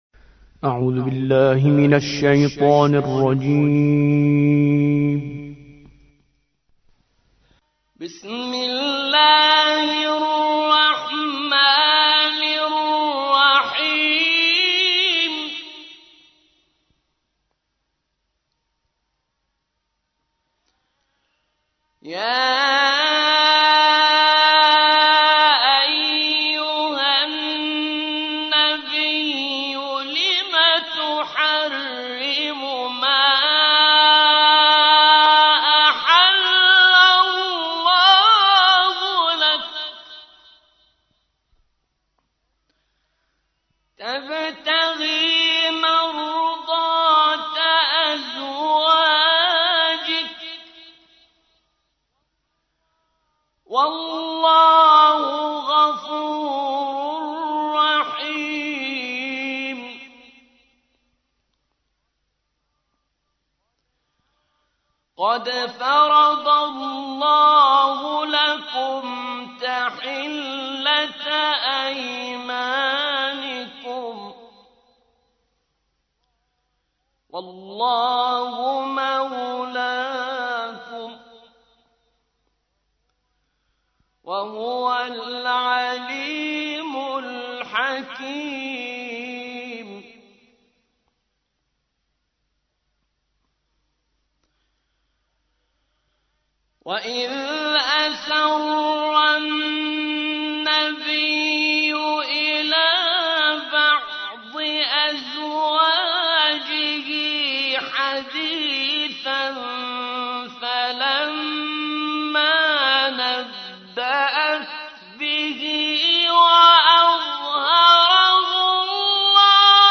تحميل : 66. سورة التحريم / القارئ كريم منصوري / القرآن الكريم / موقع يا حسين